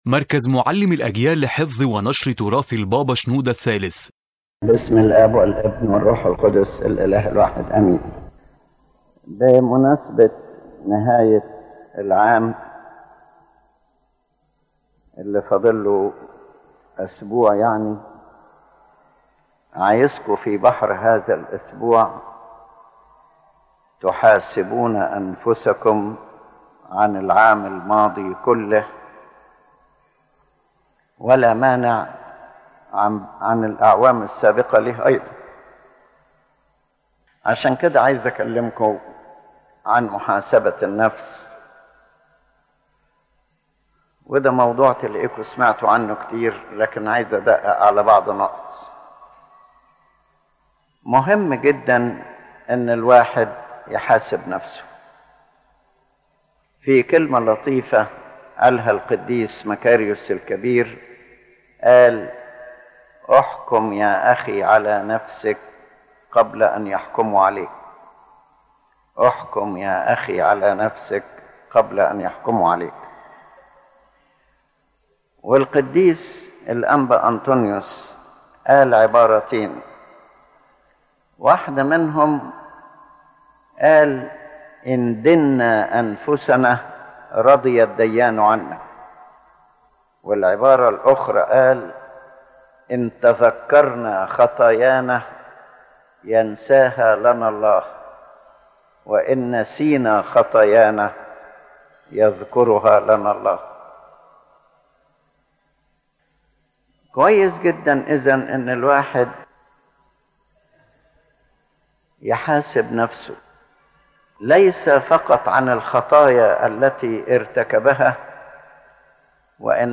The lecture focuses on the importance of self-examination as a fundamental path to true repentance and spiritual growth, especially at the end of the year and the beginning of a new one. It emphasizes that a person should not justify or pamper oneself, but rather judge oneself with love and humility in order to receive God’s mercy and remain steadfast in repentance.